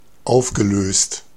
Ääntäminen
Ääntäminen US Haettu sana löytyi näillä lähdekielillä: englanti Käännös Ääninäyte Adjektiivit 1. aufgelöst Dissolved on sanan dissolve partisiipin perfekti.